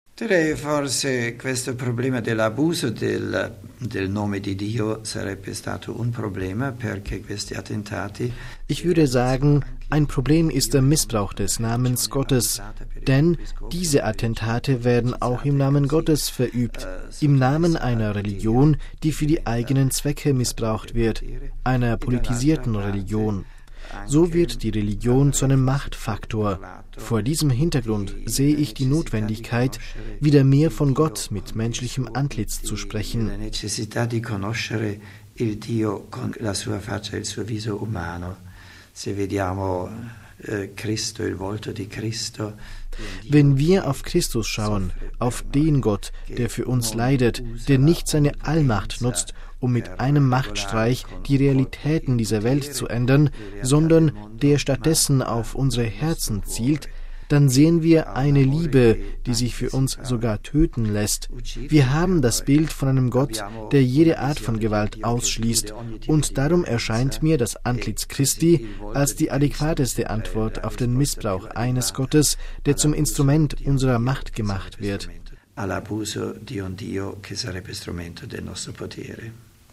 Kardinal Joseph Ratzinger, heute Papst Benedikt XVI., vor zehn Jahren in einem Interview mit Radio Vatikan über den Terror des 11. September 2001